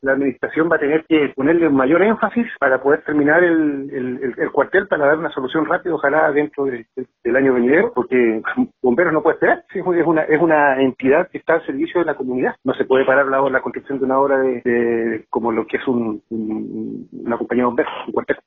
Por su parte, el también concejal Rodrigo Schnettler, afirmó que la administración municipal deben poner más énfasis en los trabajos porque Bomberos no puede seguir esperando.